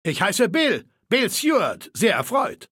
Maleold01_ms06_greeting_0005cbc6.ogg (OGG-Mediendatei, Dateigröße: 25 KB.
Fallout 3: Audiodialoge